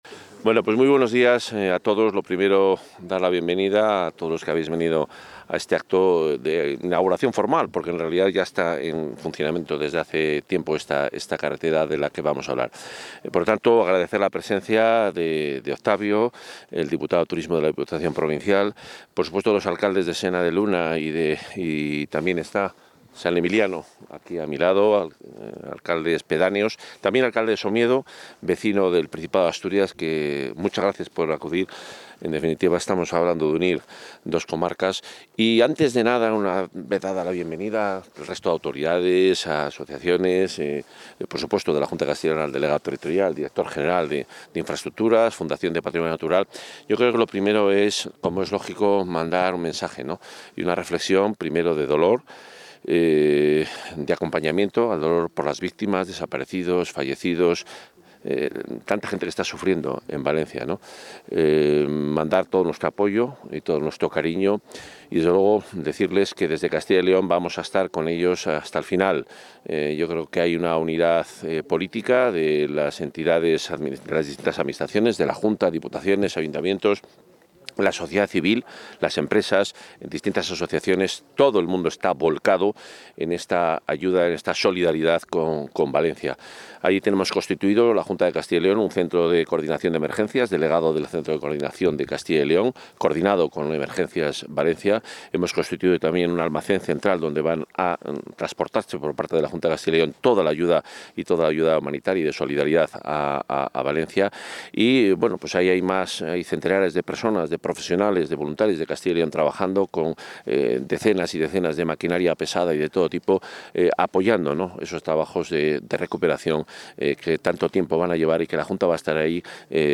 Intervención del consejero.
El consejero de Medio Ambiente, Vivienda y Ordenación del Territorio, Juan Carlos Suárez-Quiñones, ha inaugurado esta mañana la obra por la que se ha convertido el antiguo camino de La Farrapona en una carretera con las medidas de seguridad y de tránsito adecuadas.